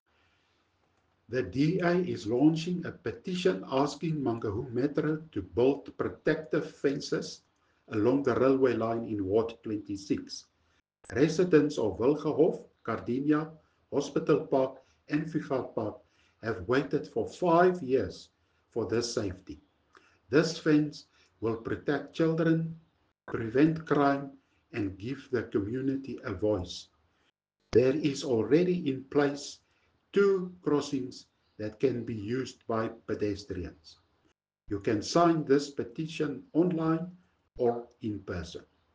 Afrikaans soundbites by Cllr Hennie van Niekerk and